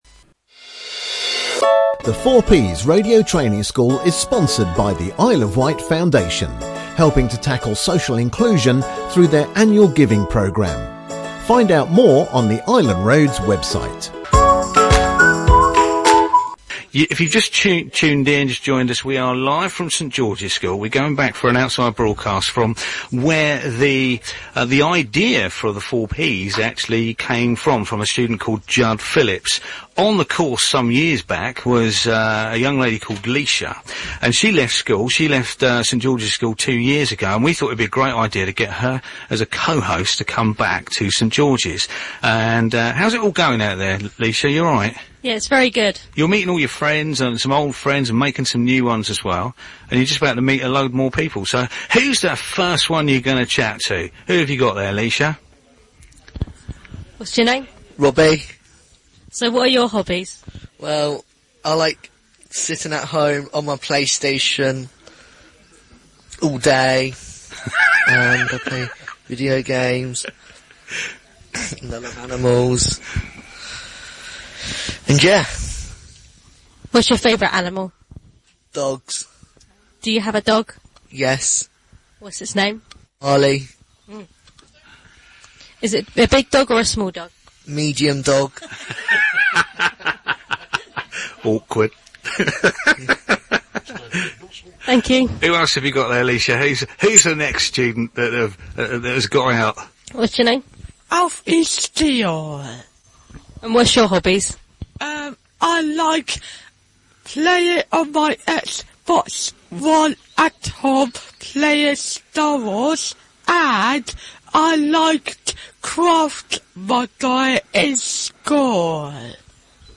The 4Ps Outside Broadcast Part2 - St Georges School